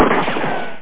1 channel
gunshot1.mp3